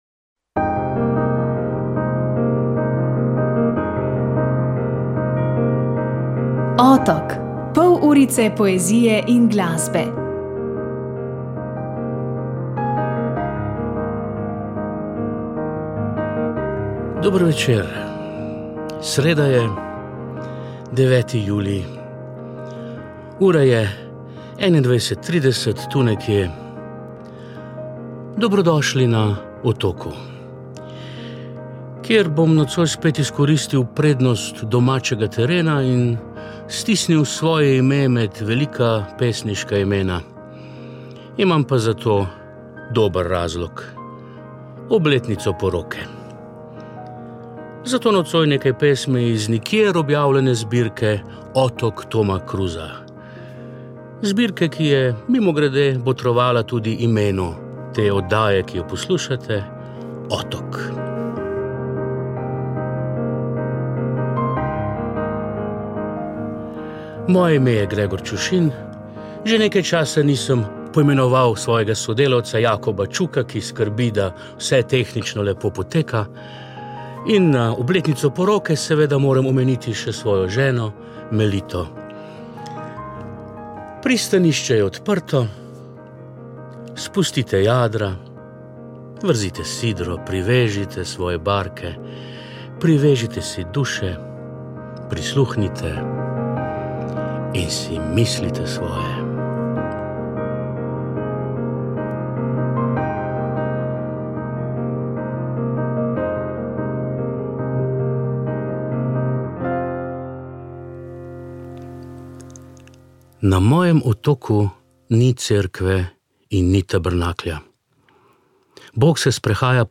Tokrat smo se spominjali teh, ki jih čas ne zanima več. Prebirali smo Pesmi iz zasmrtja pesnika Petra Kolška.
glasba poezija